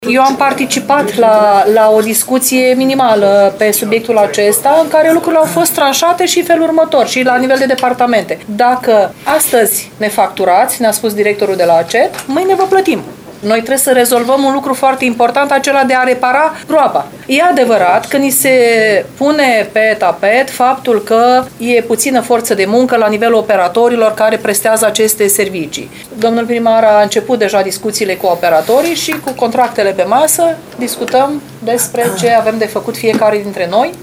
Administratorul municipiului Suceava IRINA VASILCIUC a adăugat că o problemă în derularea reparațiilor la străzi rămâne lipsa forței de muncă la operatorii din domeniu.